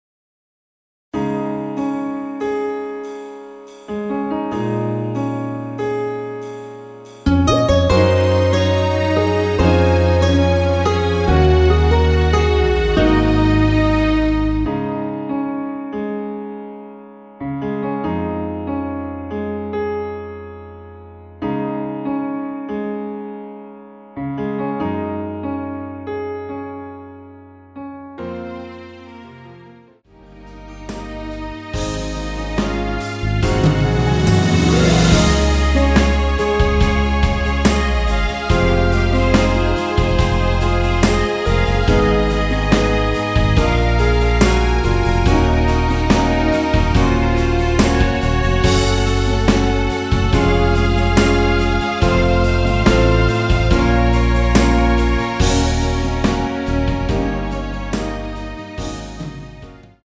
원키에서(-1)내린 MR입니다.
Db
앞부분30초, 뒷부분30초씩 편집해서 올려 드리고 있습니다.
중간에 음이 끈어지고 다시 나오는 이유는